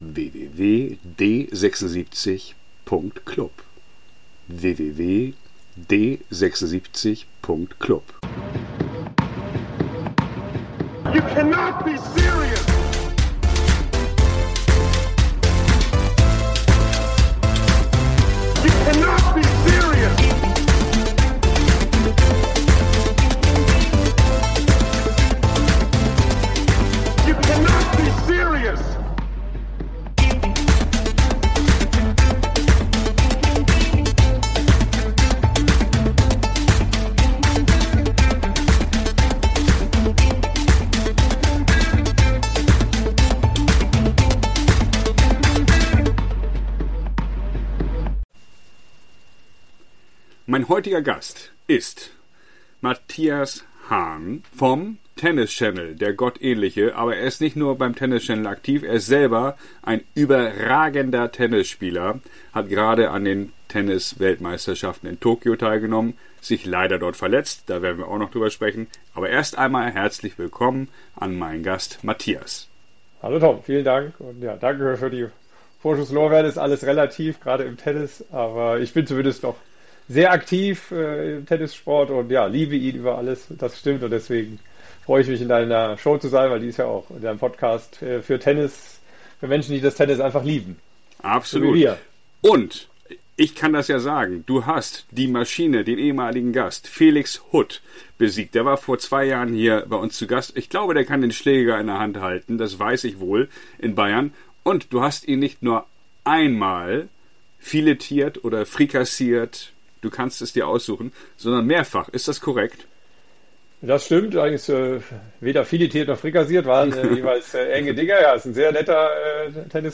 Ex-Profis, Trainer, Ex-Talente, Journalisten, Turnierveranstalter - bekannte und unbekannte Tennisspieler erzählen ihre unterhaltsamen Anekdoten des weißen Sports.
Remote per Riverside aufgenommen oder im feinen Hamburger Hotel Tortue.
Recorded remotely via Riverside or in the fine Hamburg Hotel Tortue.